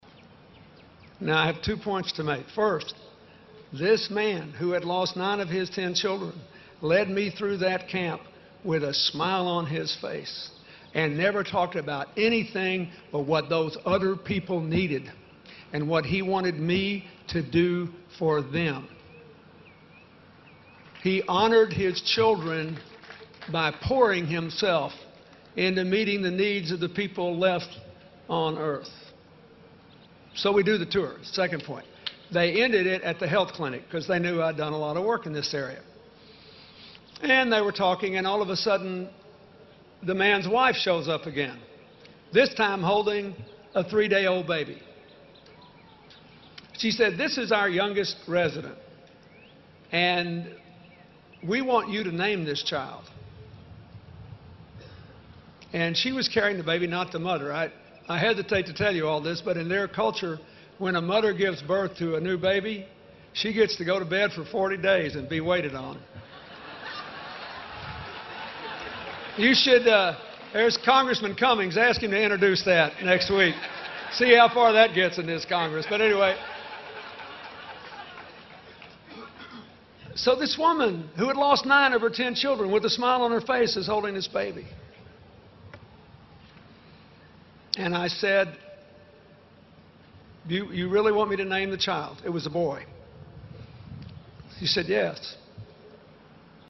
公众人物毕业演讲 第135期:2013年克林顿在霍华德大学(14) 听力文件下载—在线英语听力室